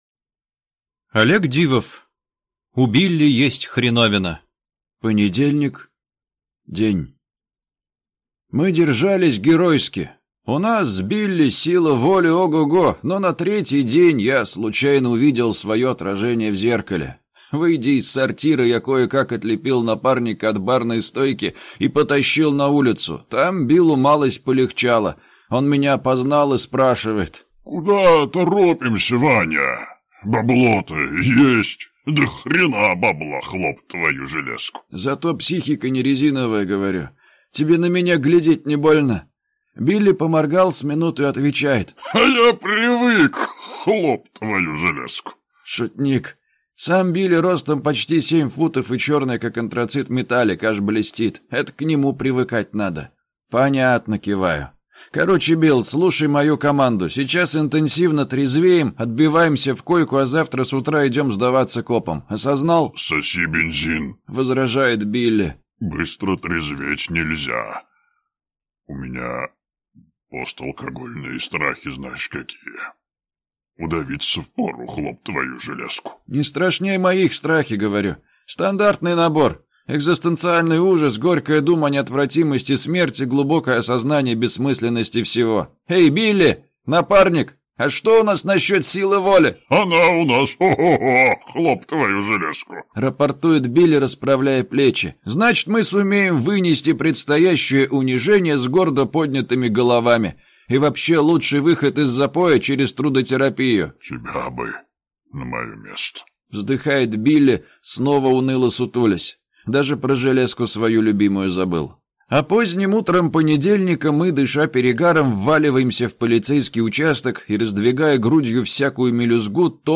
Аудиокнига У Билли есть хреновина | Библиотека аудиокниг